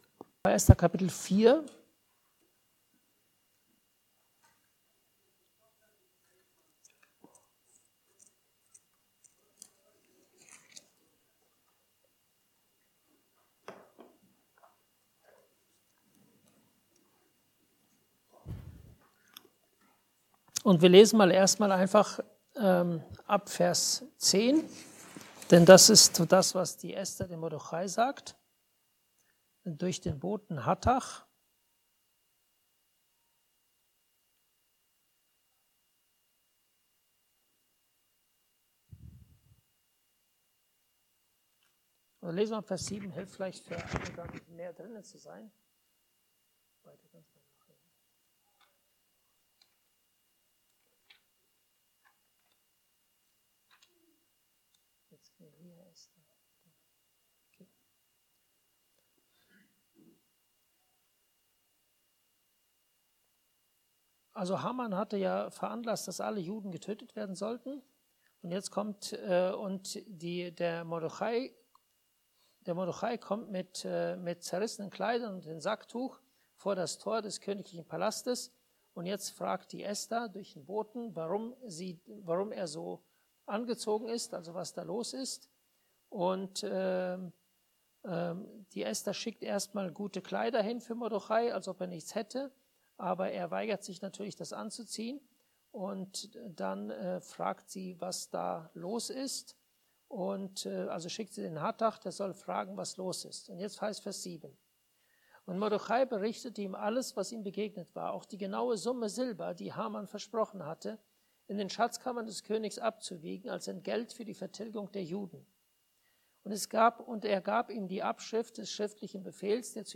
Esther – als Lektion für uns (Andacht Gebetsstunde)